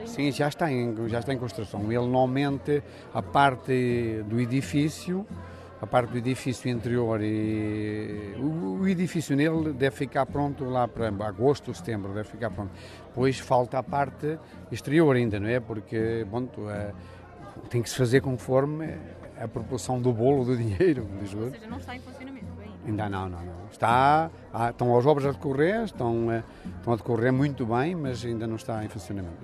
Declarações à margem da VIII edição da feira da Cereja. As obras já começaram no interior e a previsão de finalização é de setembro, como acrescentou o presidente da junta de freguesia: